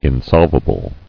[in·solv·a·ble]